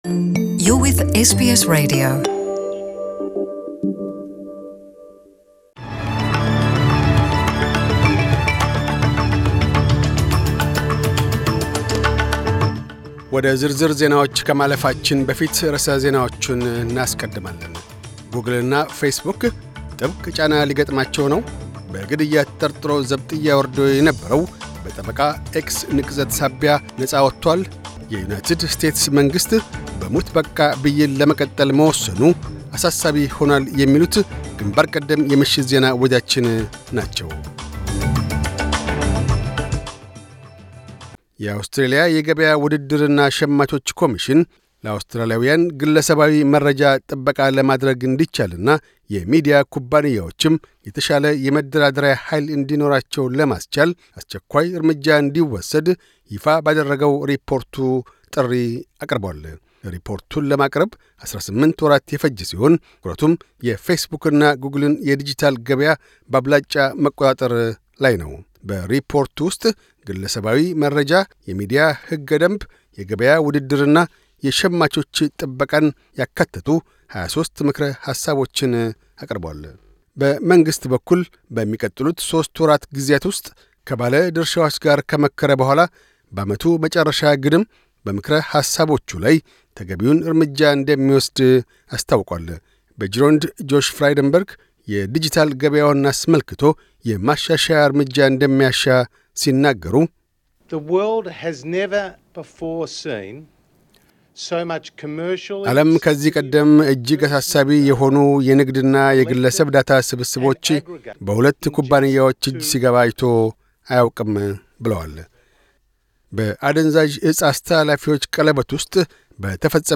Australian and International News